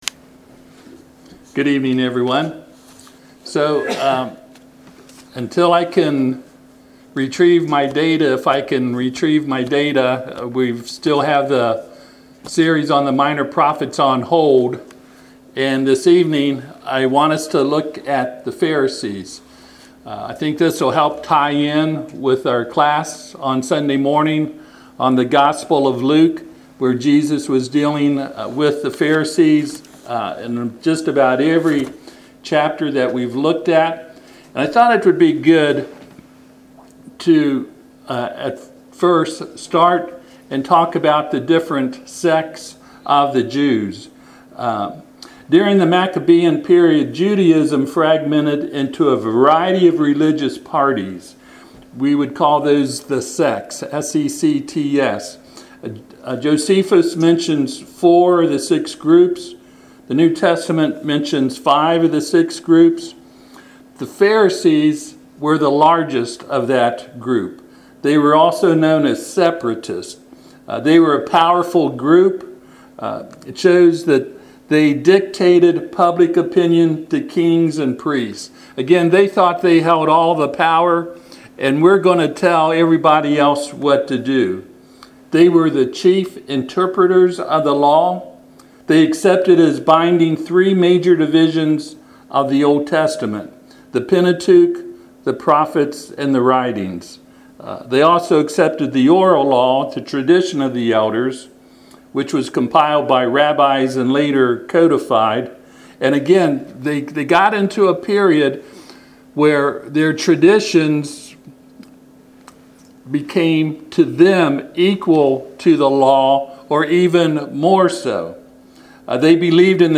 Matthew 23:27-33 Service Type: Sunday PM https